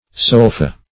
solfa - definition of solfa - synonyms, pronunciation, spelling from Free Dictionary
Sol-fa \Sol`-fa"\, v. t.